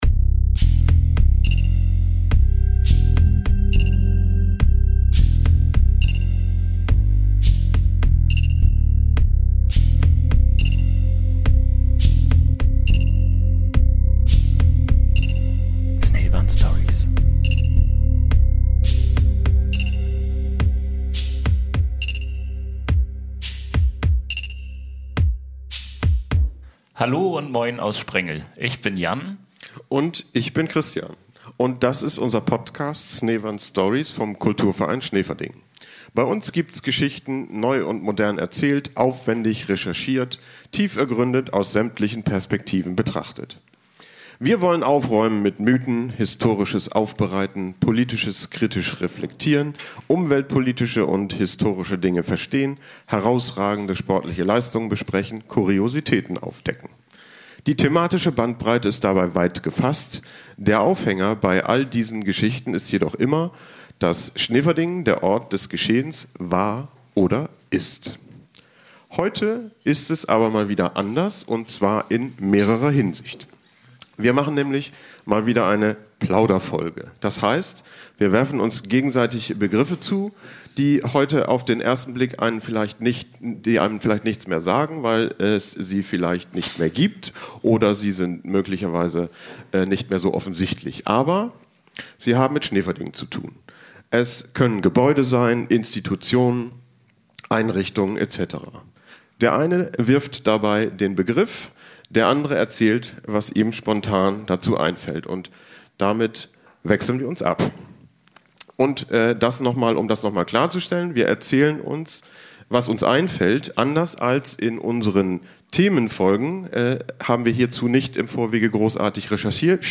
Das Besondere daran war, dass die Gäst*innen interaktiv die Folge mitgestalten konnten.